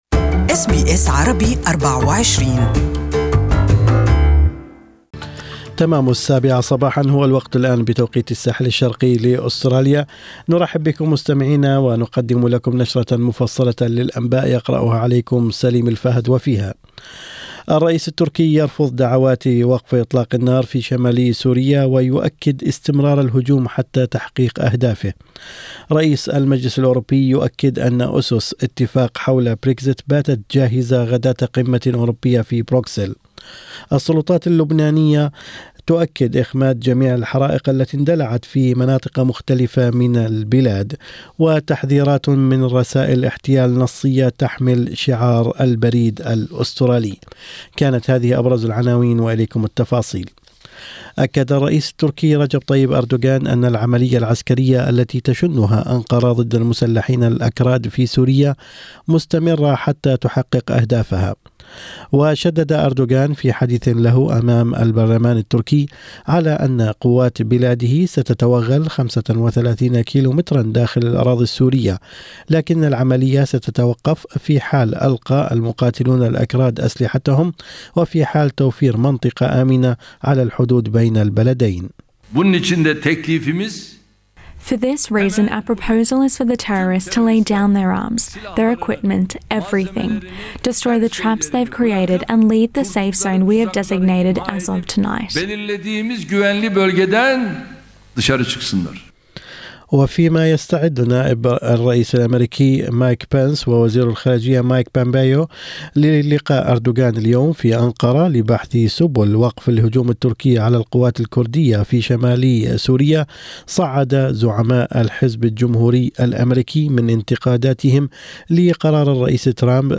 Morning News: warnings against new scam text messages